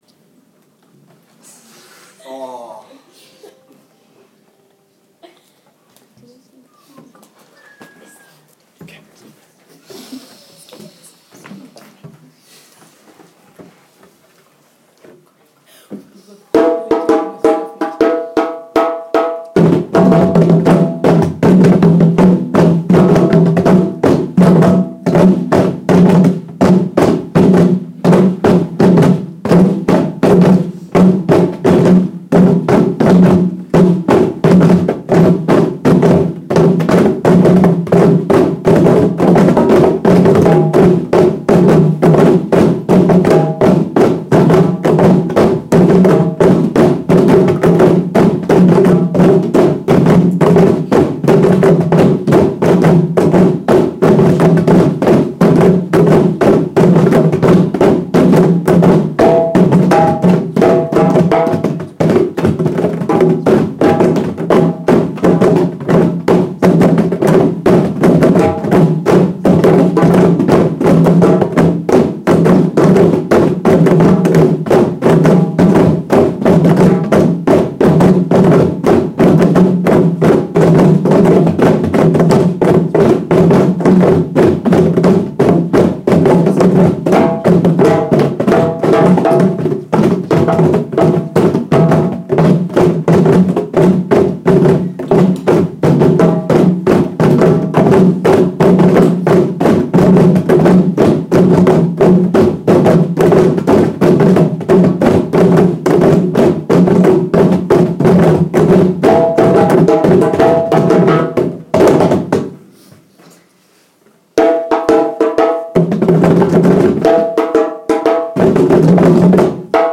10 African Drumming